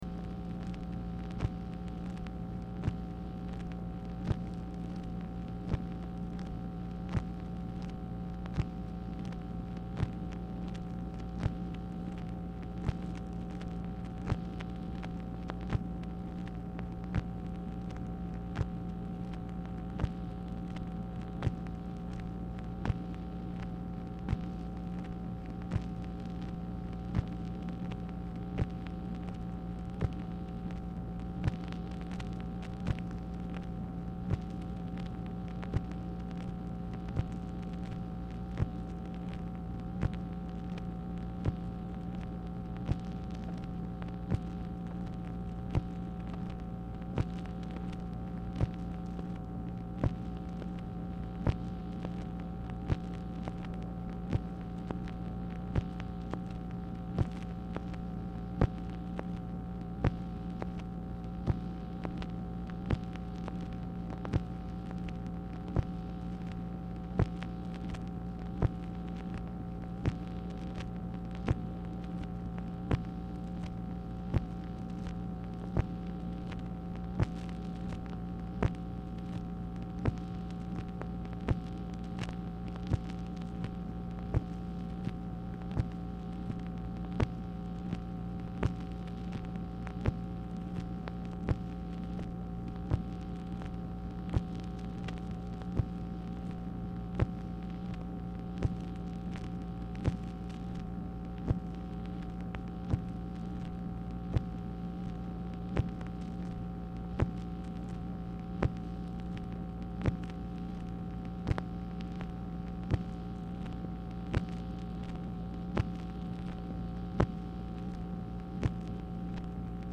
Telephone conversation # 10956, sound recording, MACHINE NOISE, 10/16/1966, time unknown | Discover LBJ
Format Dictation belt